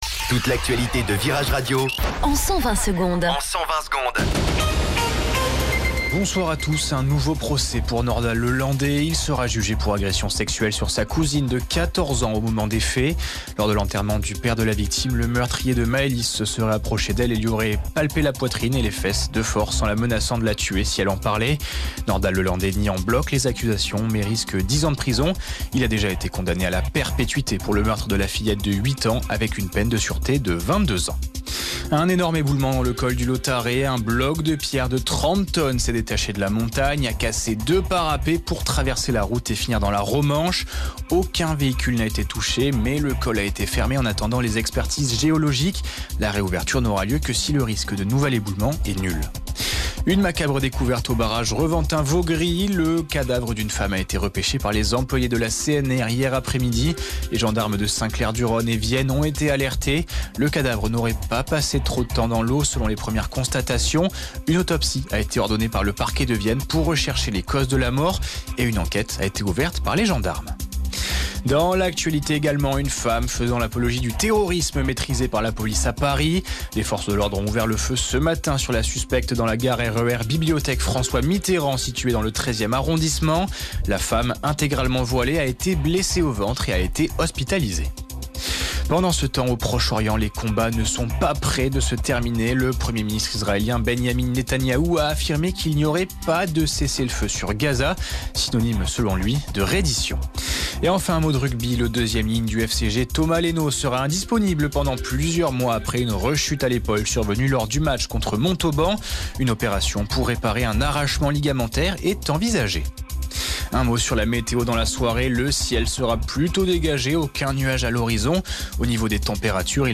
Flash Info Grenoble